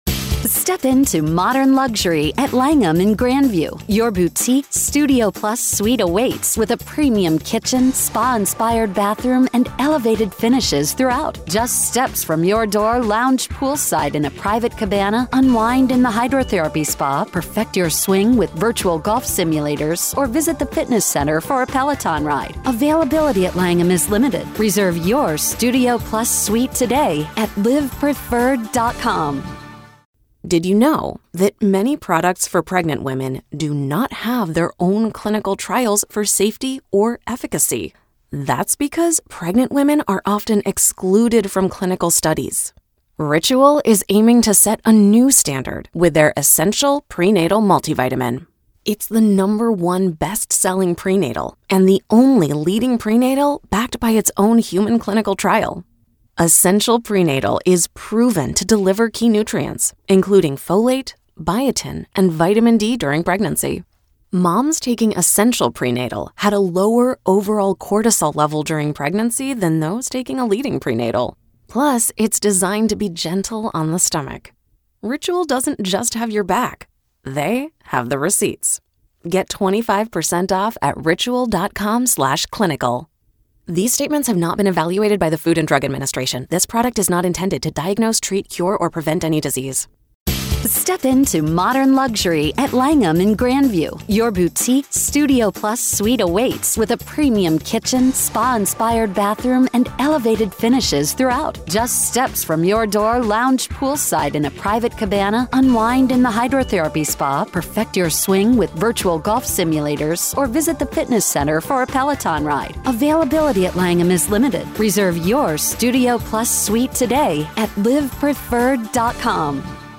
Dr. Katherine Ramsland Interview Behind The Mind Of BTK Part 5